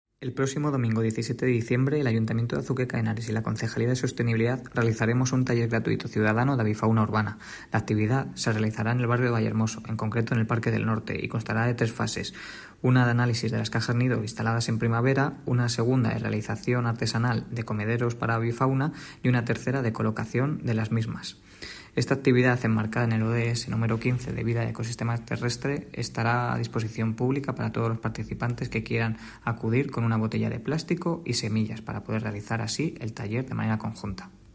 Declaraciones del concejal Rodrigo Vasco